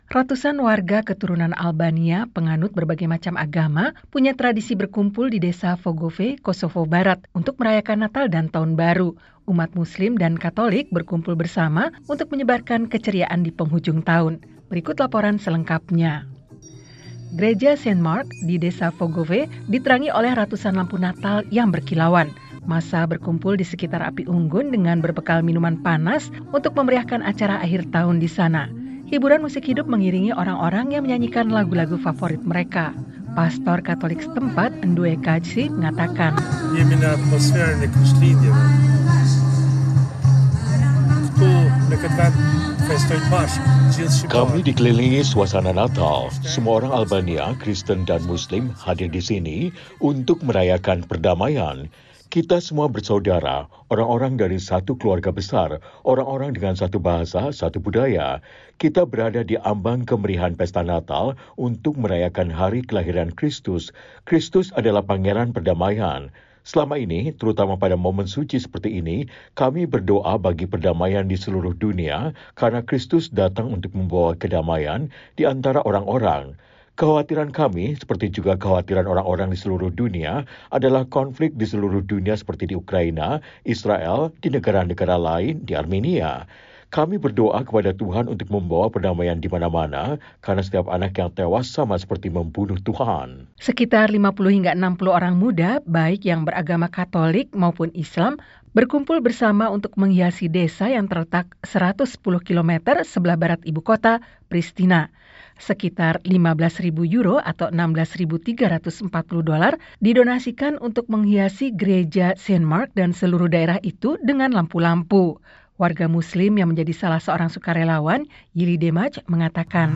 Massa berkumpul di sekitar api unggun dengan berbekal minuman panas untuk memeriahkan acara akhir tahun di sana.
Hiburan musik hidup mengiringi orang-orang yang menyanyikan lagu-lagu favorit mereka.